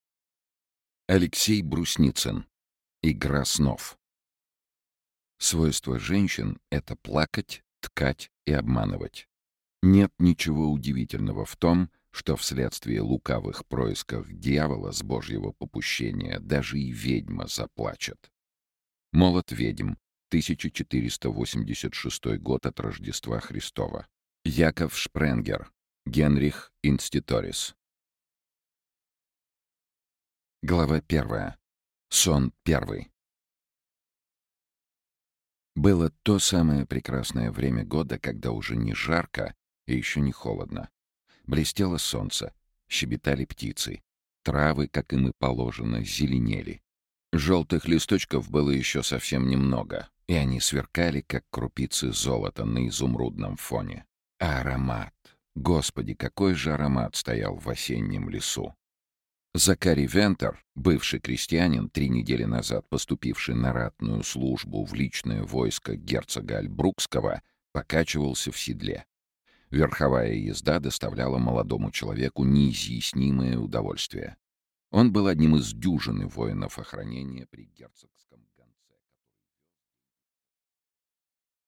Аудиокнига Игра снов | Библиотека аудиокниг
Aудиокнига Игра снов Автор Алексей Брусницын Читает аудиокнигу Сергей Чонишвили.